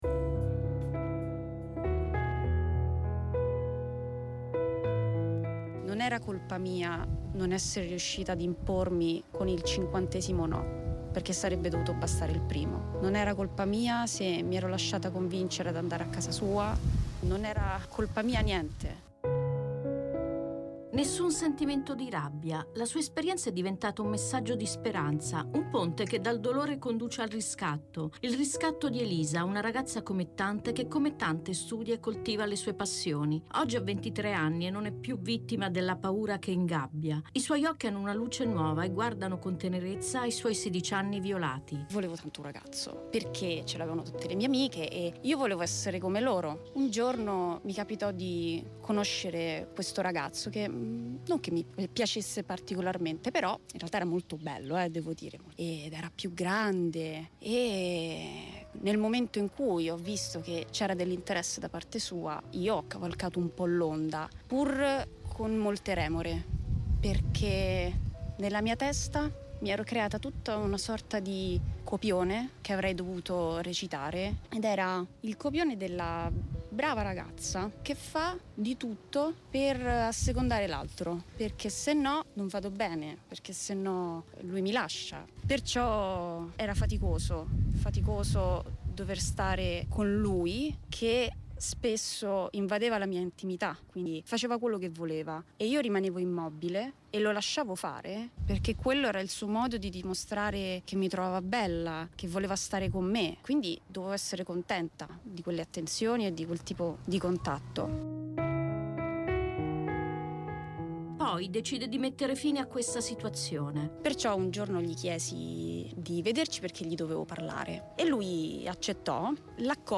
Testimonianze